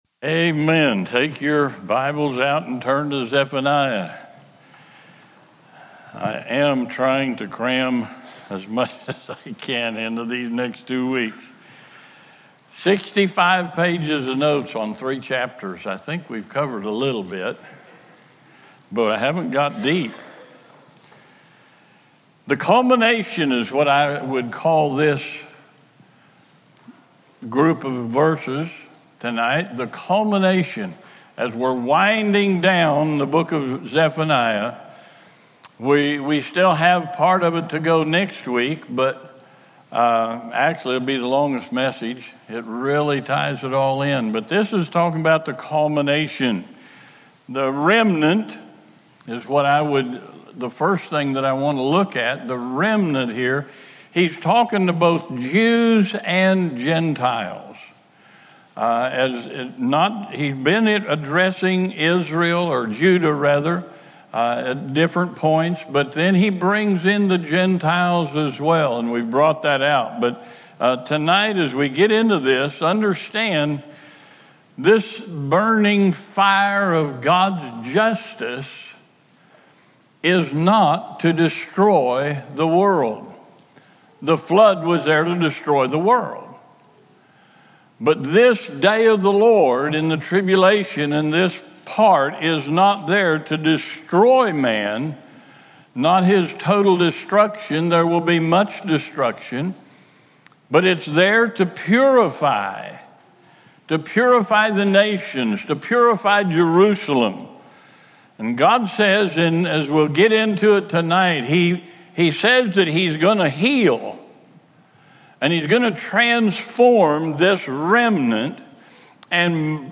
Wednesday Night